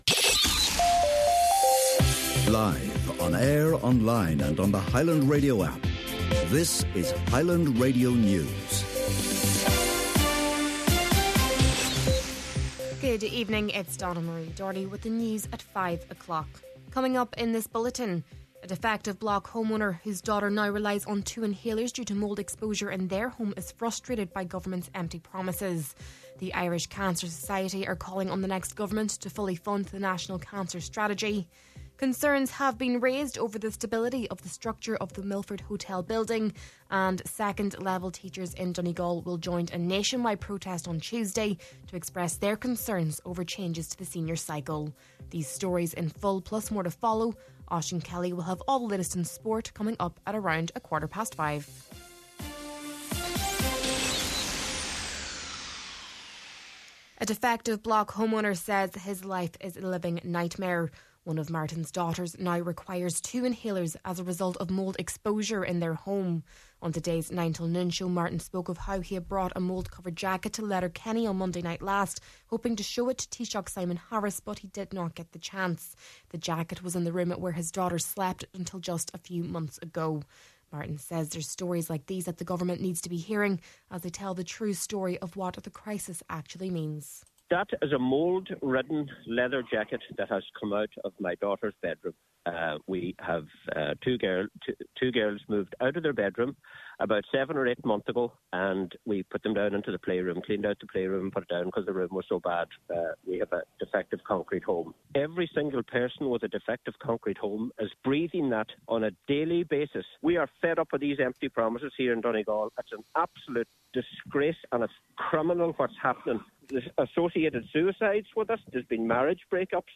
Main Evening News, Sport and Obituary Notices – Wednesday, November 13th